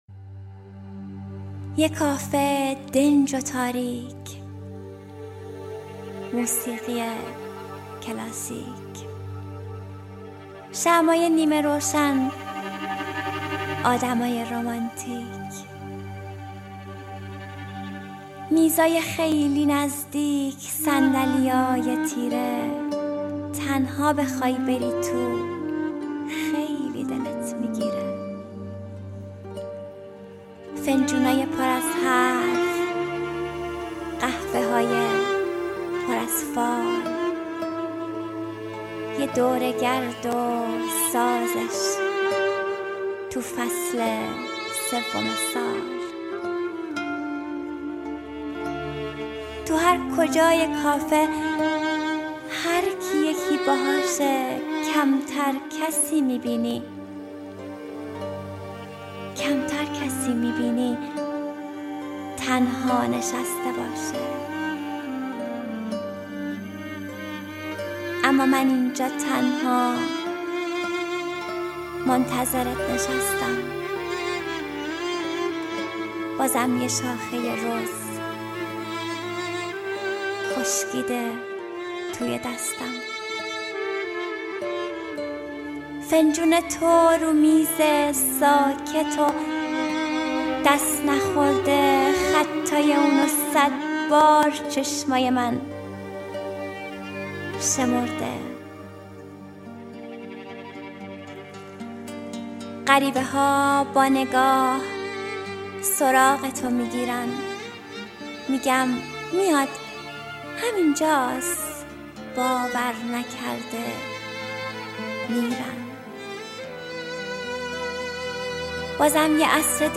دانلود دکلمه کافی شاپ با صدای مریم حیدرزاده